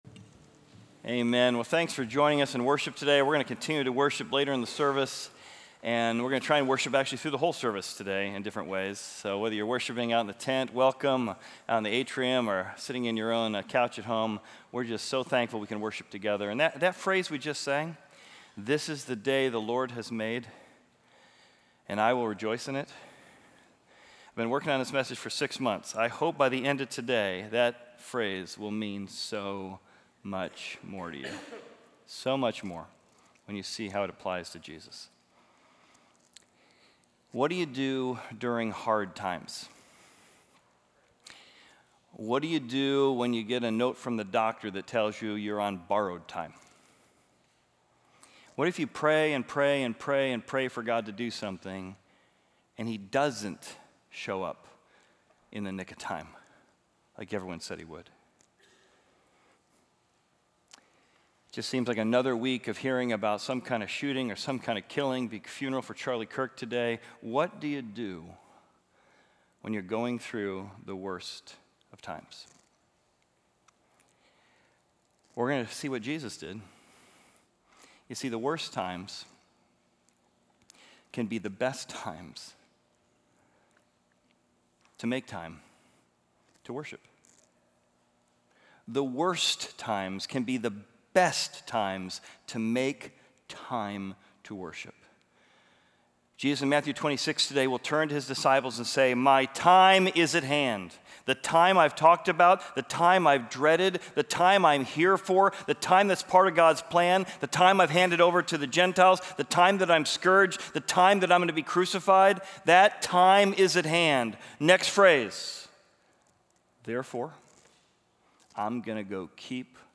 Equipping Service / Matthew: The Brightest Worship In The Darkest Hour / Worshipping Through Music